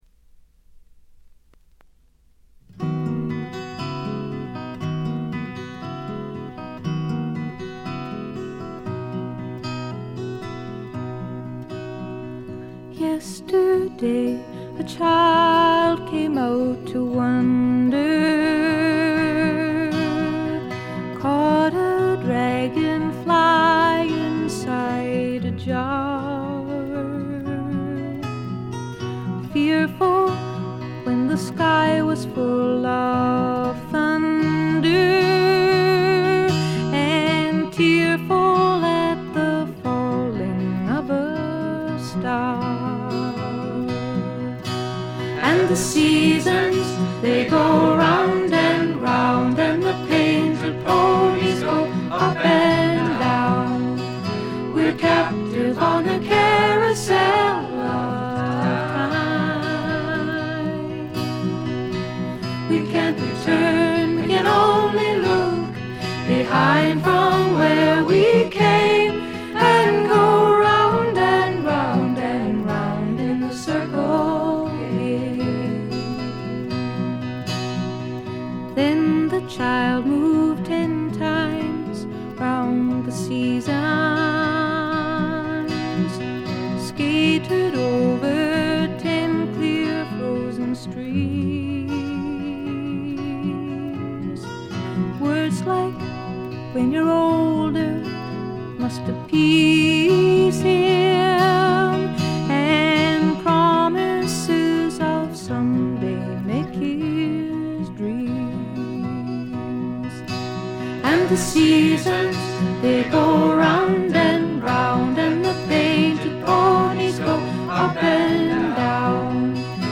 軽微なチリプチ少々。散発的なプツ音が2-3箇所。
美しいことこの上ない女性シンガー・ソングライター名作。
試聴曲は現品からの取り込み音源です。
Recorded At - A&M Studios